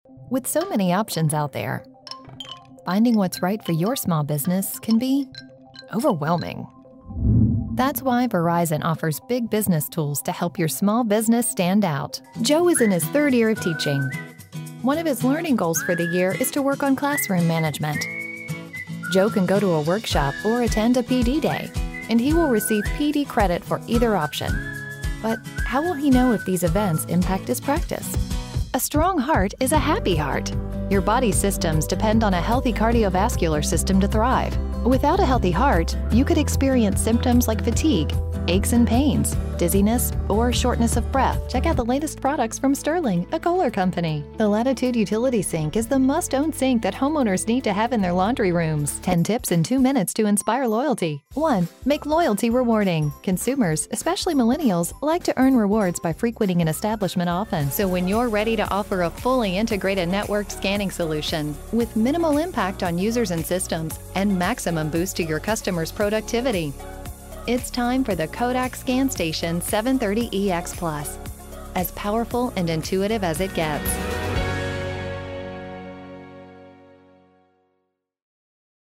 Female Voice Over, Dan Wachs Talent Agency.
Warm, Authoritative, Spokesperson.
Corporate